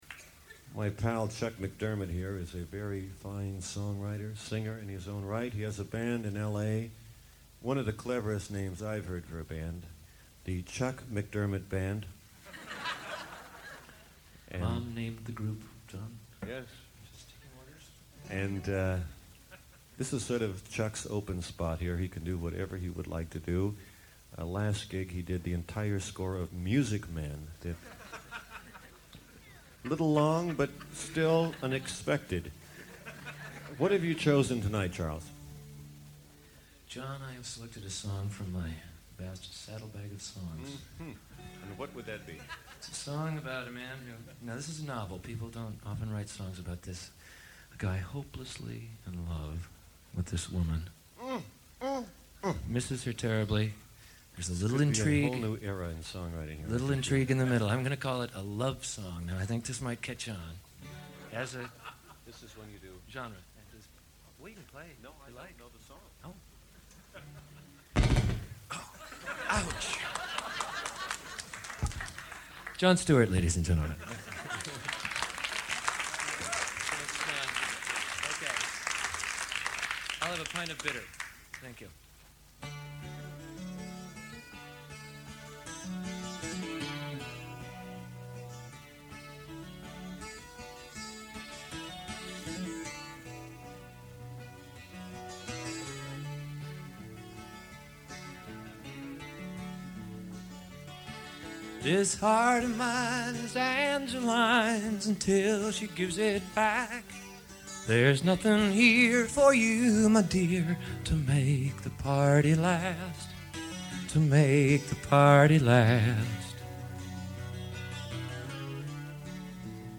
The 1984 tour must have been great fun.
Their onstage repartee’ was also fun for the audience.